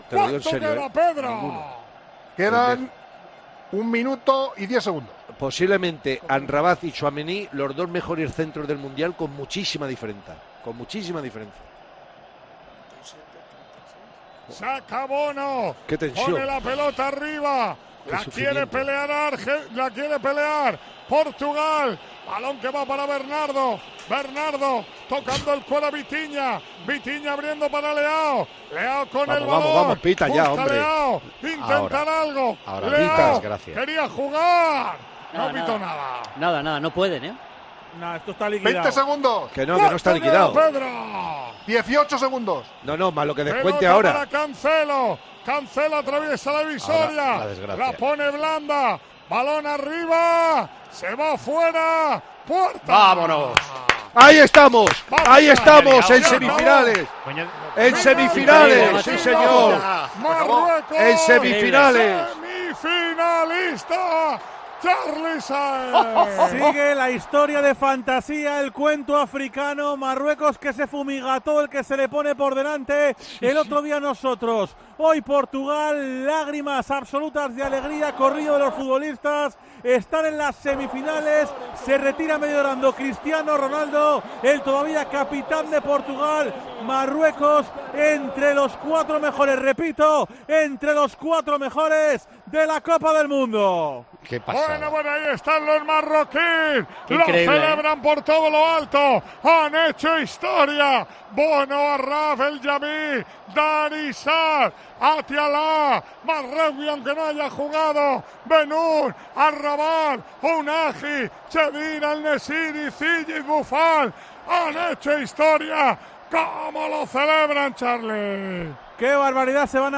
Escucha cómo ha narrado Manolo Lama el último minuto del Marruecos-Portugal que dio la clasificación de la selección africana a semifinales.